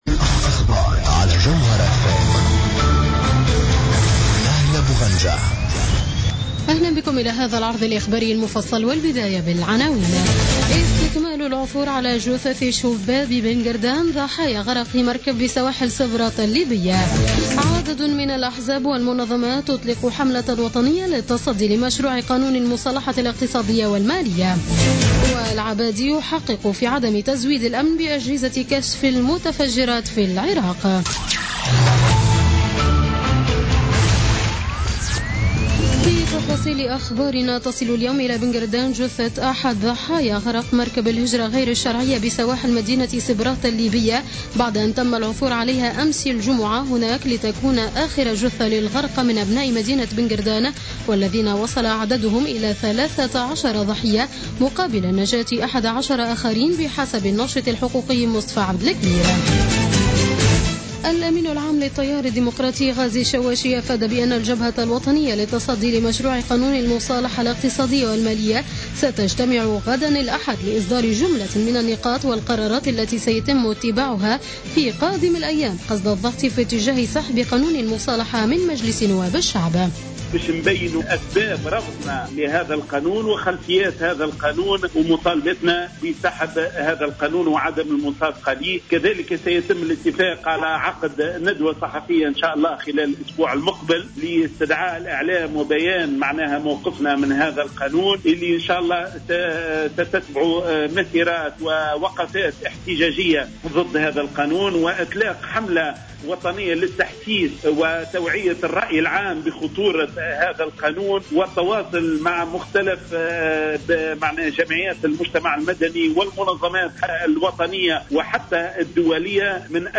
Journal Info 19h00 du samedi 9 Juillet 2016